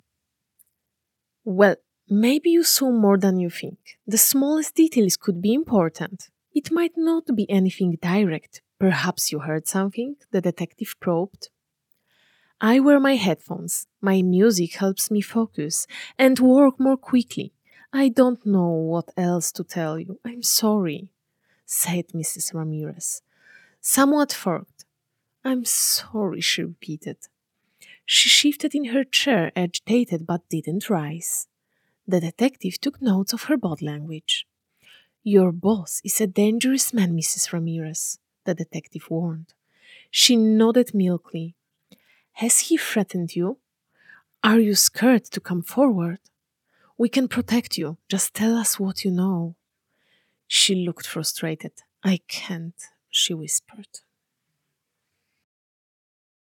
ENGLISH DATASET
VOIC004 - TTS Research - English (North American).wav